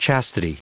Prononciation du mot chastity en anglais (fichier audio)
Prononciation du mot : chastity